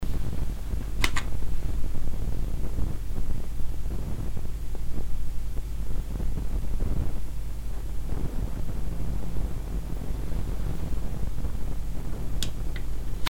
Уровень и характер фонового шума.
Уважаемые профессионалы, помогите понять природу проблемы: время от времени, а пишу через мик я непостоянно, я слышу в канале такой вот шум.
Этот же шум — неровный, всплесками, уровень подпрыгивает выше -50 дб.
Файл я слегка нормализовал, чтобы лучше было слышно.